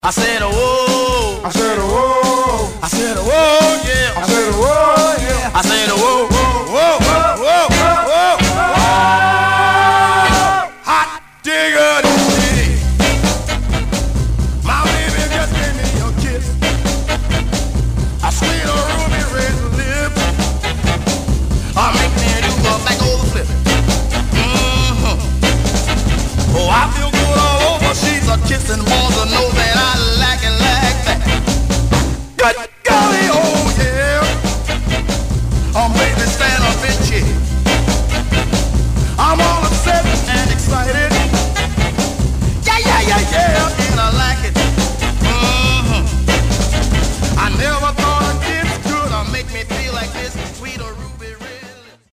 Some surface noise/wear Stereo/mono Mono
Soul